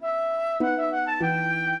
flute-harp
minuet9-8.wav